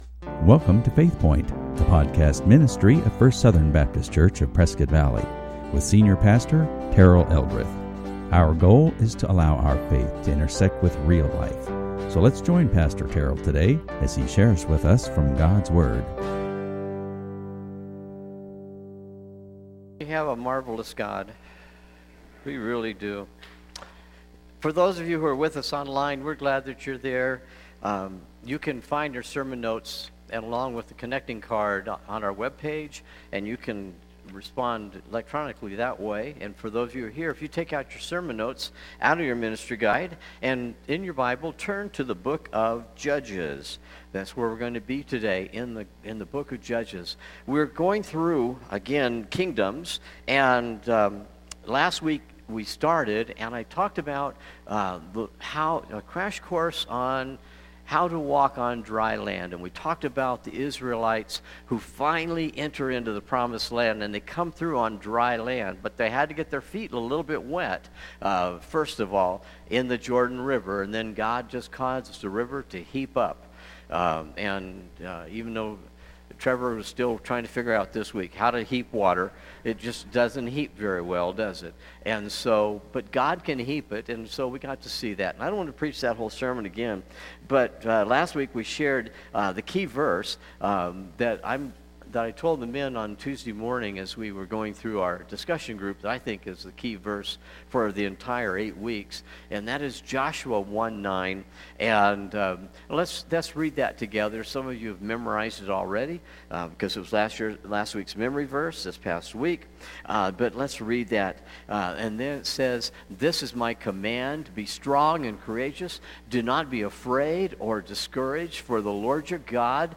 Listen to sermons from FSBC of Prescott Valley